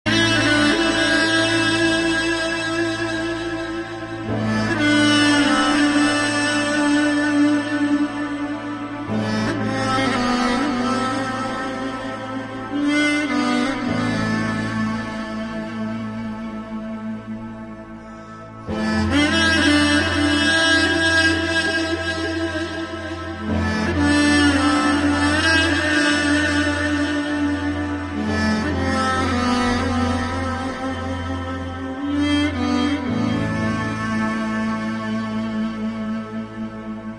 • Качество: 102, Stereo
грустные
саундтреки
спокойные
без слов
инструментальные
восточные
дудук